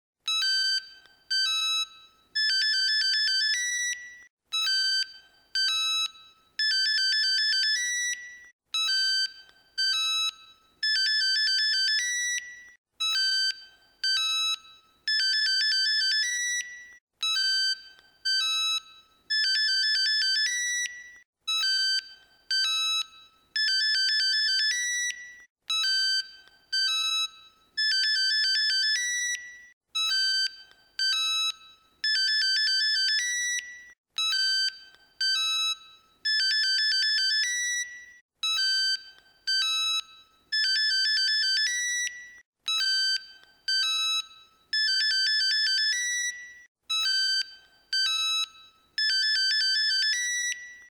без слов
Electronica
звонкие
пищалка
Звонкий рингтон на ваш телефон